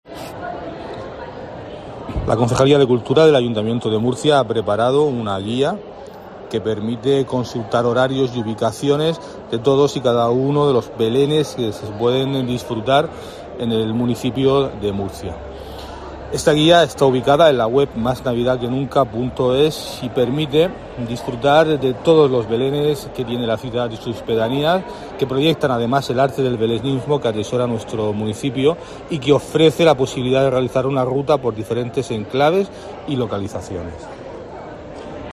Pedro García Rex, concejal de Cultura, Turismo y Deportes